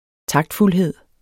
Udtale [ ˈtɑgdfulˌheðˀ ] Betydninger det at være taktfuld